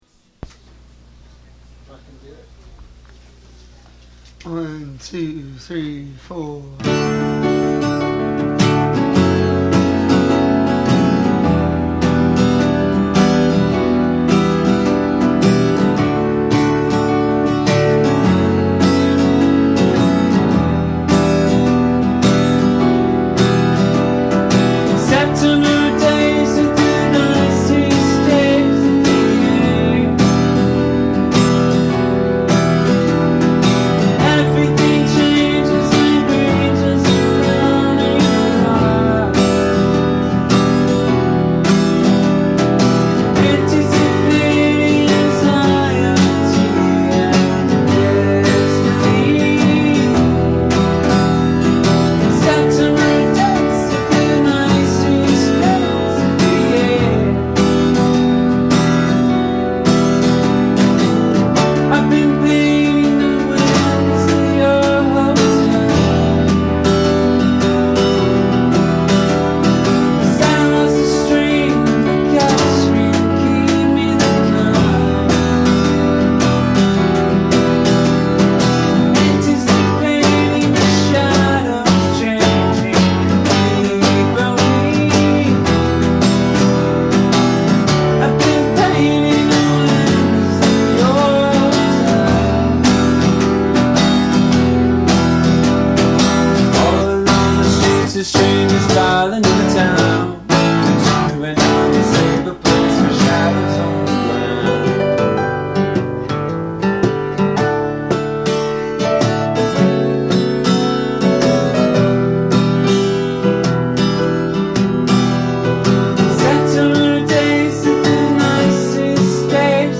Acoustic Kickstarter Reward